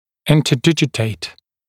[ˌɪntə’dɪʤɪteɪt][ˌинтэ’диджитэйт]смыкаться, вступать в фиссурно-бугорковый контакт (о зубах)